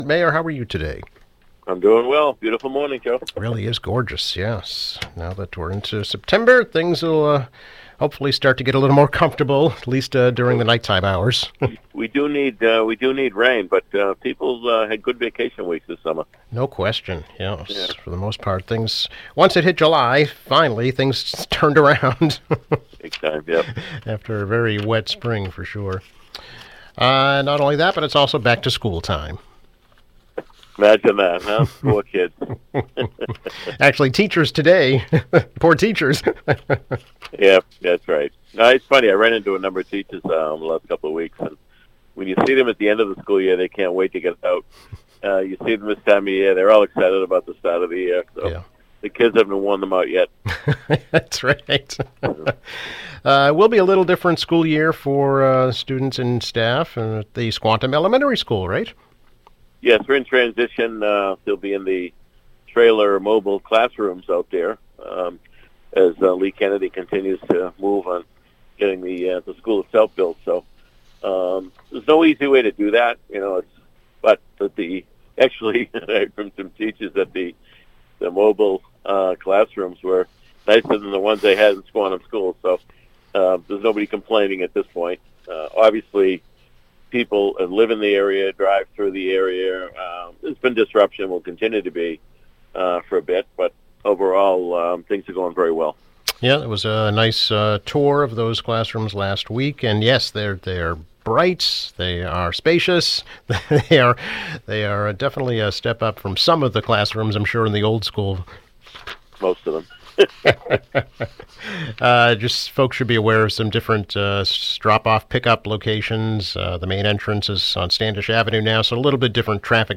Quincy Mayor Thomas Koch speaks about the discovery of so-called forever chemicals in firefighter gear, the new Squantum School, and preliminary election day.